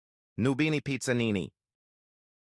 noobini pizzanini steal a brainrot Meme Sound Effect